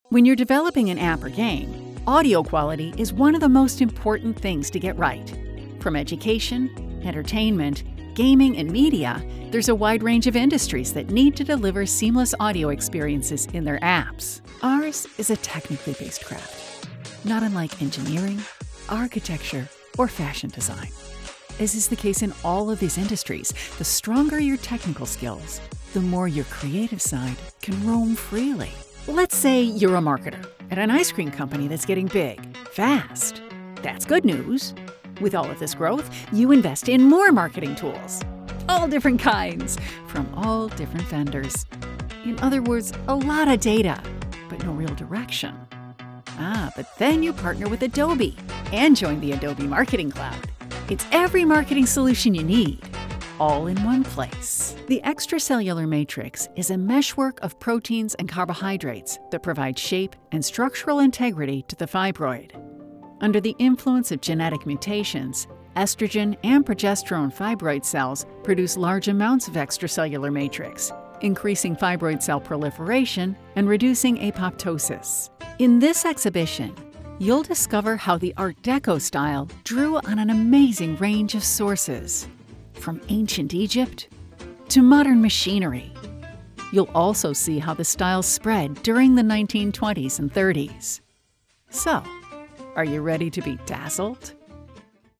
Narration
narrationdemo-feb23_mixdown2.mp3